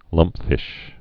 (lŭmpfĭsh)